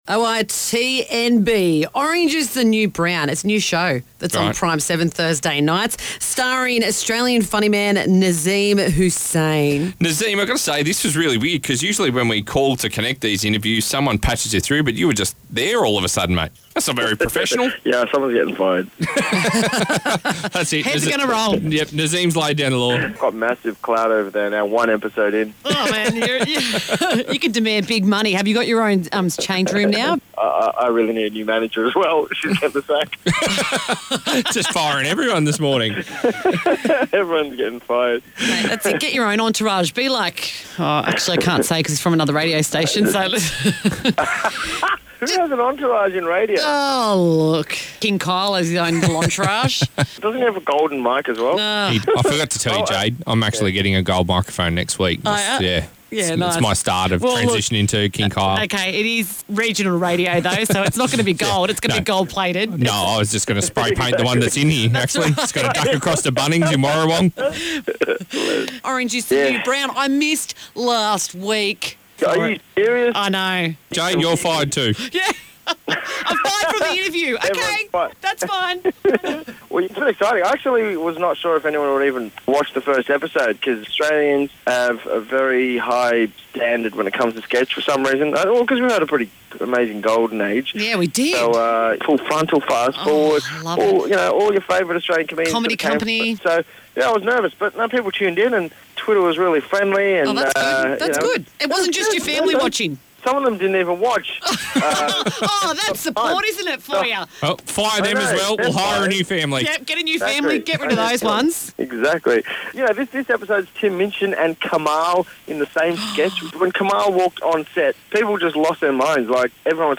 Orange Is The New Brown star Nazeem Hussain had a chat with the brekky guys about his brand new show and also reflects on some of Australia's greatest comedy shows.